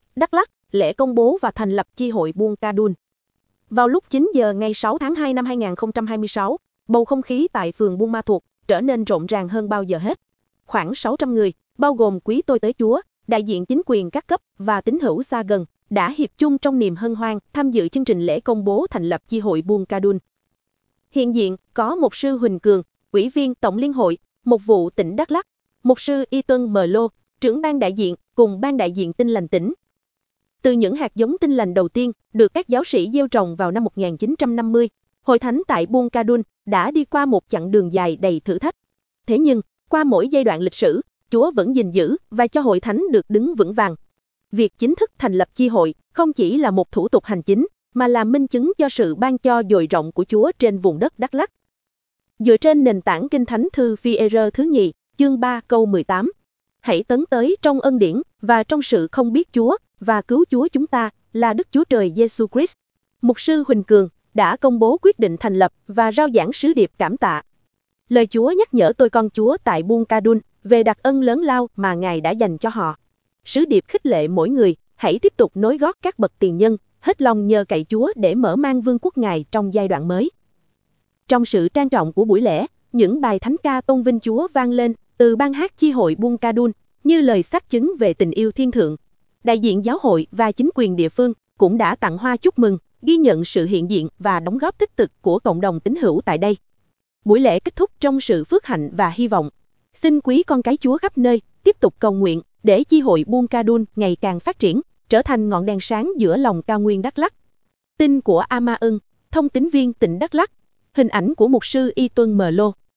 Đắk Lắk: Lễ Công bố Thành lập Chi Hội Tin Lành Buôn Kdun